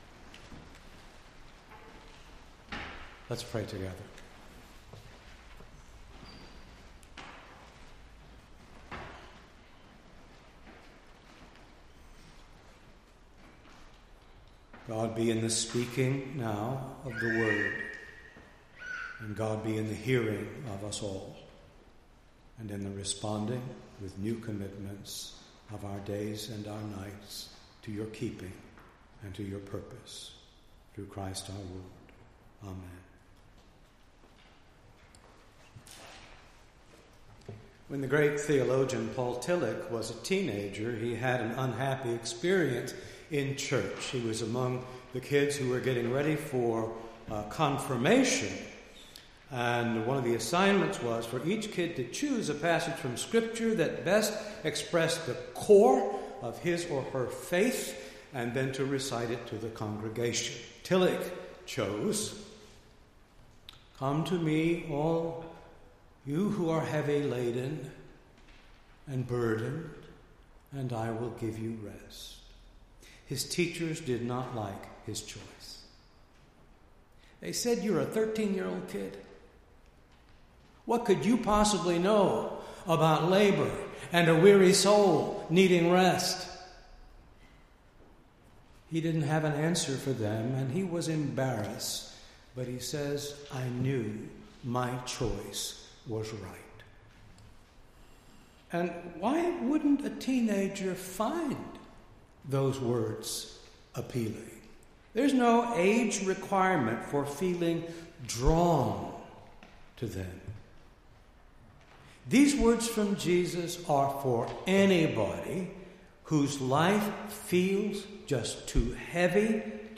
7-9-17-sermon.mp3